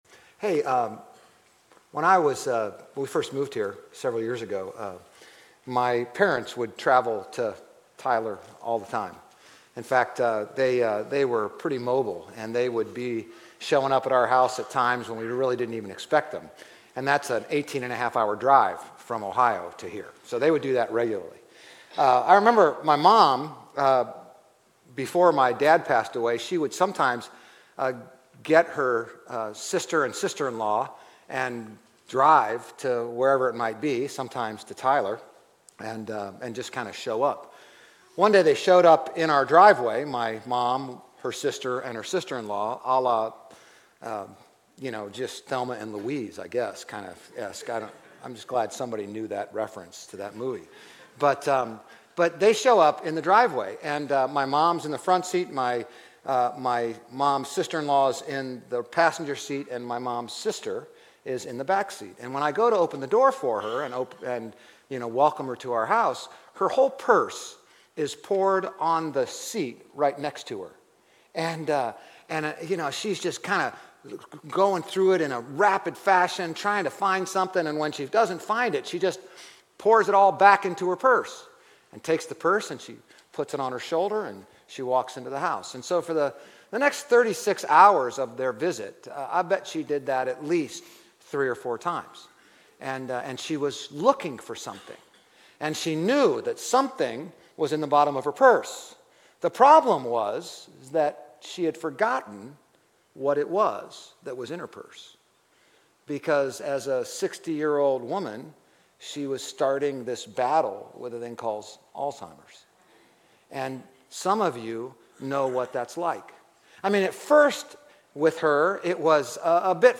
GCC-OJ-October-1-Sermon.mp3